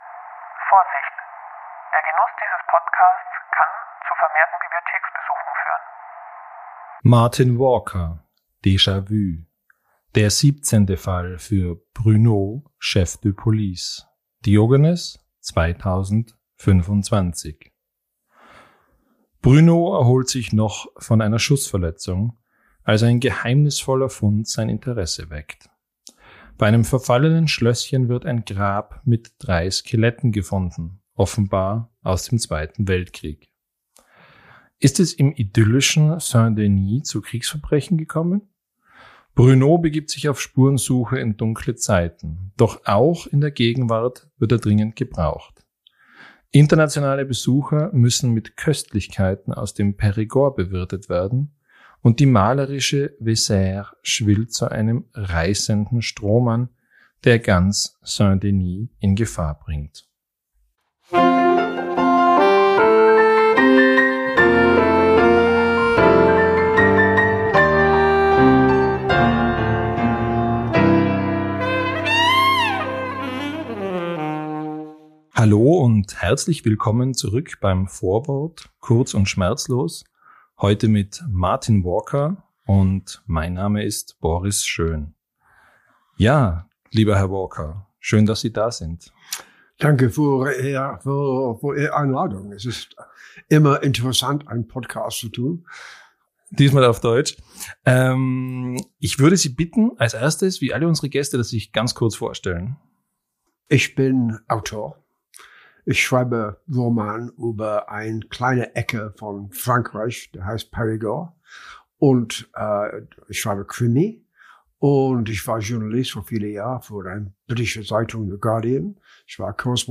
Er schwärmt vom Essen, vom Périgord, von Croissants und Wein. Exklusiv fürs Vorwort gibt er das Interview für unsere Zuhörerinnen und Zuhörer dabei auf Deutsch!